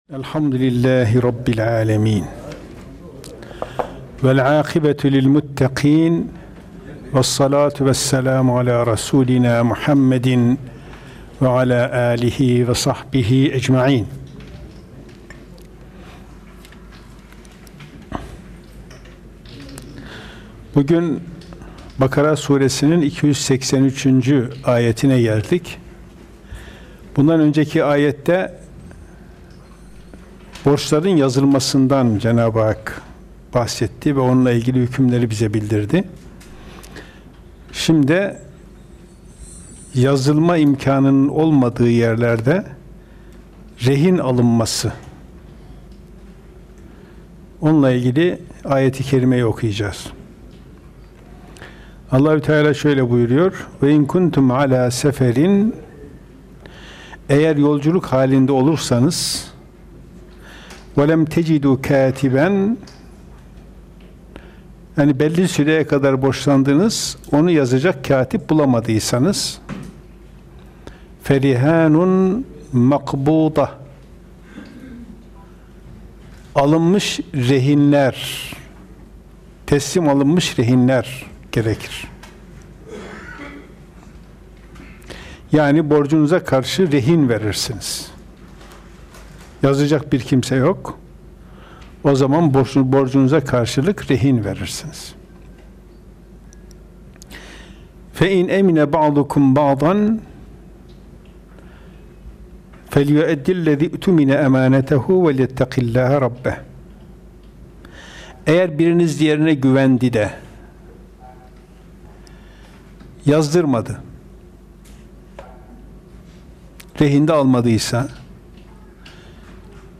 Ayet – Vasiyet ve Rehin – Kuran Dersi